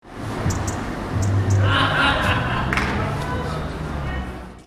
Bird chirping and people laughing at Castle Quays